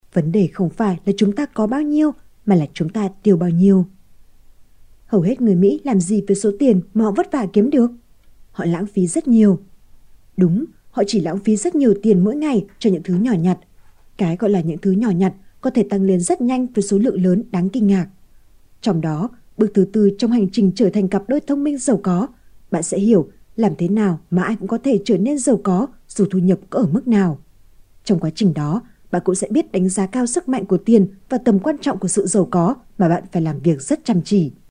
女越南01 越南语女声 略成熟 稳重 讲故事 课件工程讲解 低沉|激情激昂|大气浑厚磁性|沉稳|娓娓道来|科技感|积极向上|时尚活力|神秘性感|调性走心|亲切甜美|感人煽情|素人 00:00 01:28 点击下载 点击收藏 女越南01 越南语女声 成熟 课件学越南语 低沉|激情激昂|大气浑厚磁性|沉稳|娓娓道来|科技感|积极向上|时尚活力|神秘性感|调性走心|亲切甜美|感人煽情|素人 00:00 01:28 点击下载 点击收藏